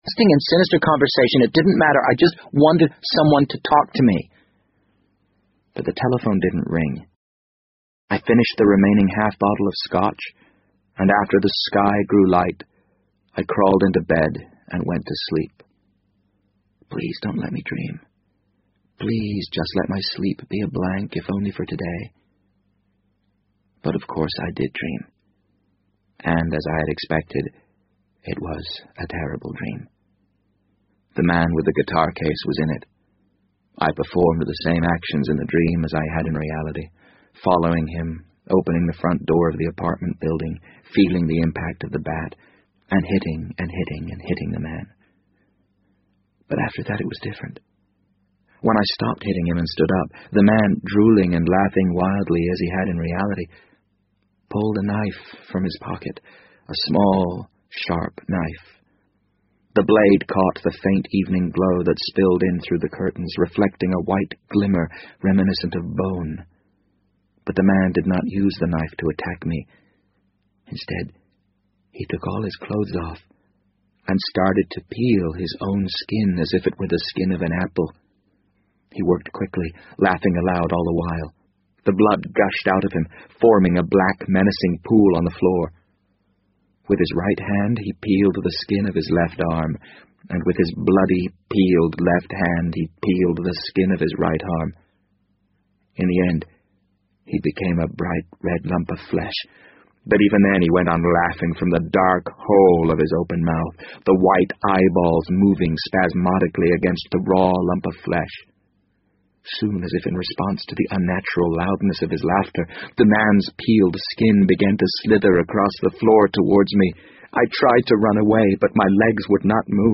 BBC英文广播剧在线听 The Wind Up Bird 009 - 5 听力文件下载—在线英语听力室